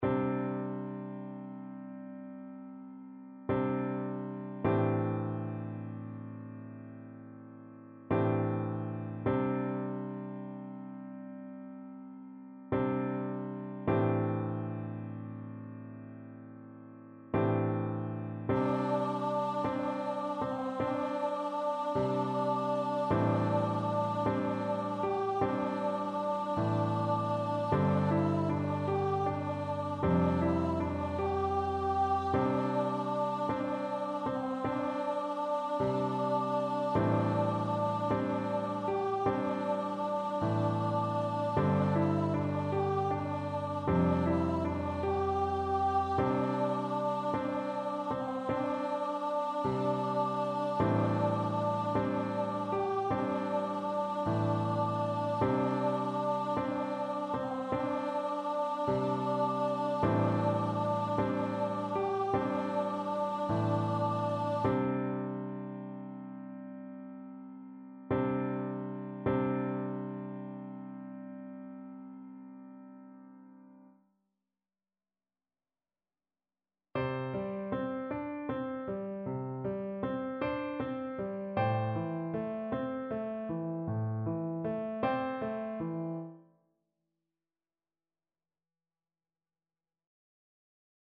a meditative gathering song
It is a centering song.
Accompaniment